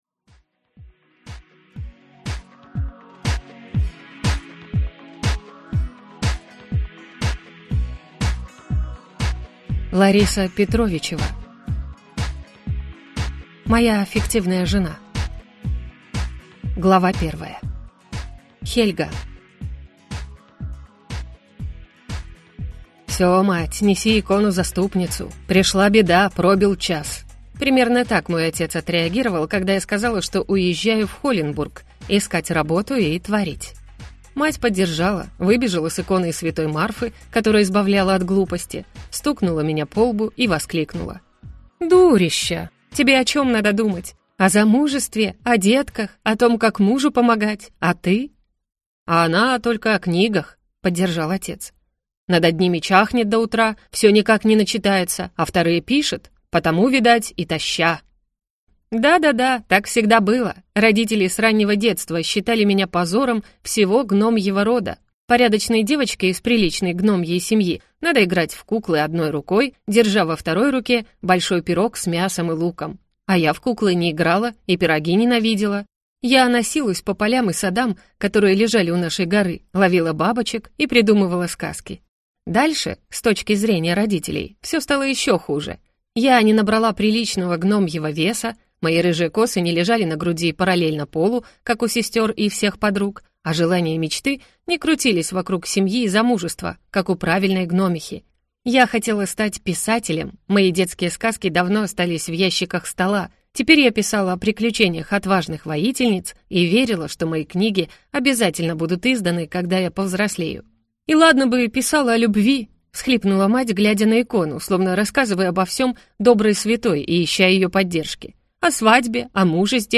Аудиокнига Моя фиктивная жена | Библиотека аудиокниг